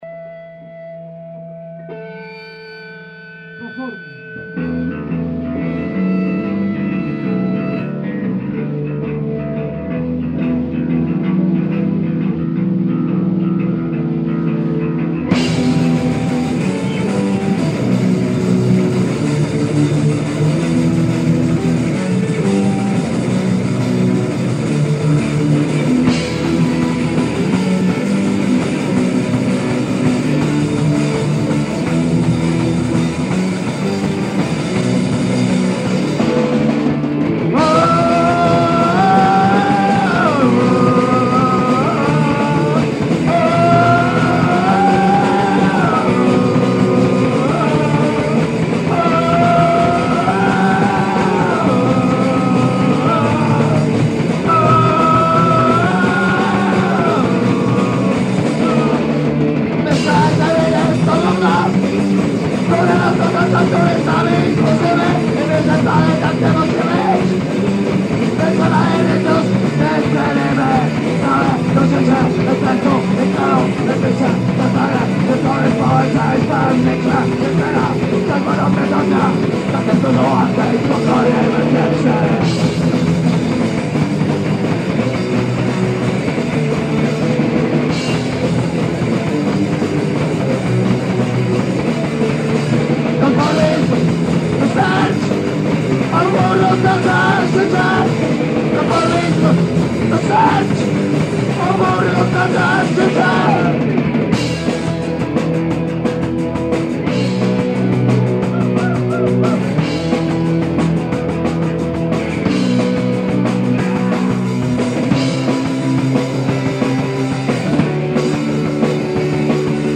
Nagrań dokonano za pomocą decka i 2 mikrofonów.